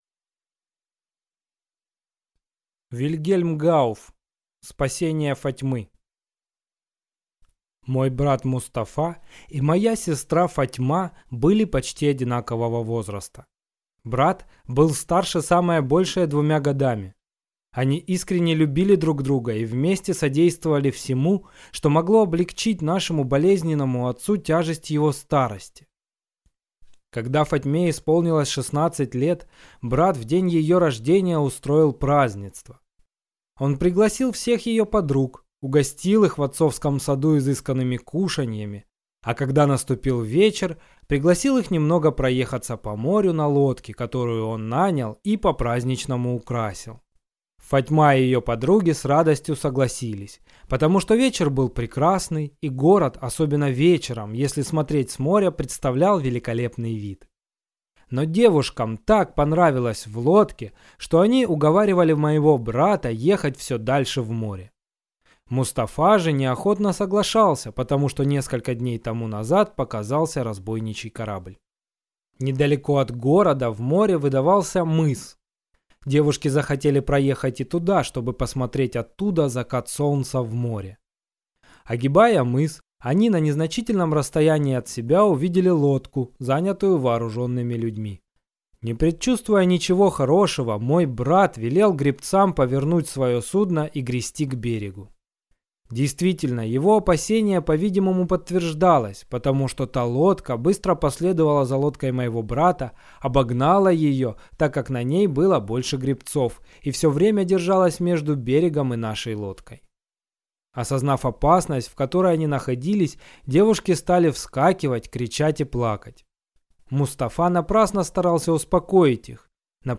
Аудиокнига Спасение Фатьмы | Библиотека аудиокниг